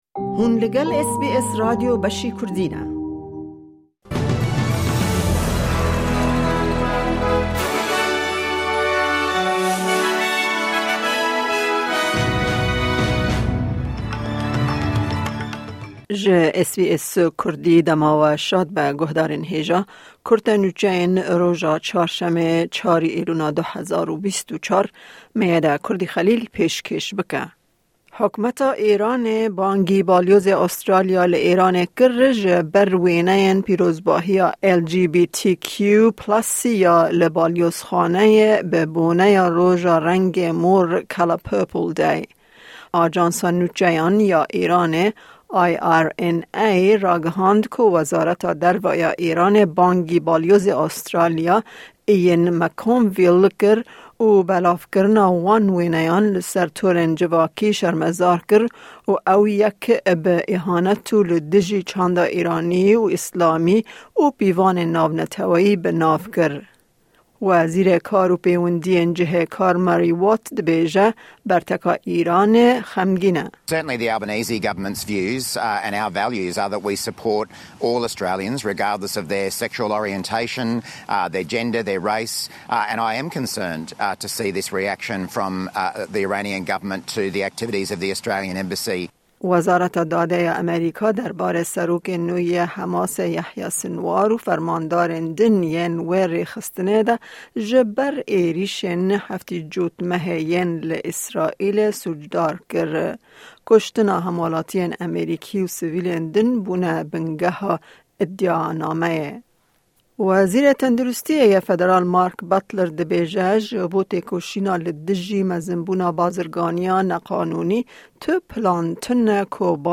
Kurte Nûçeyên roja Çarşemê 4î Îlona 2024